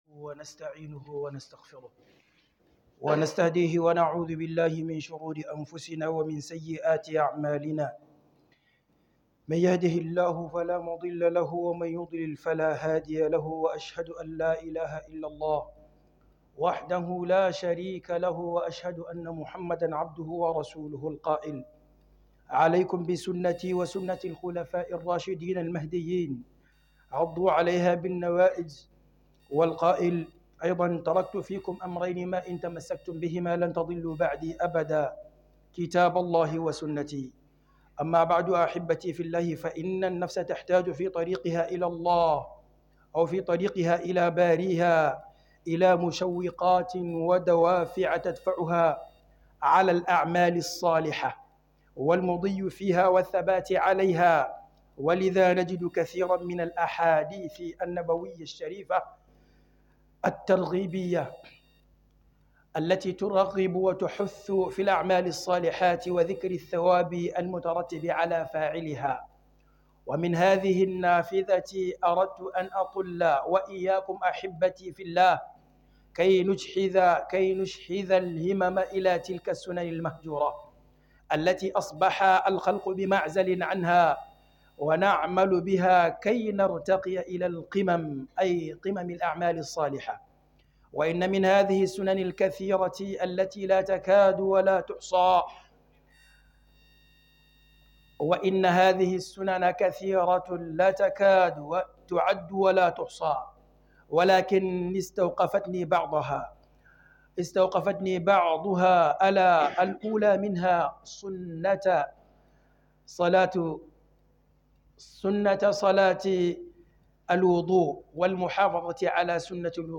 Book KHUDUBA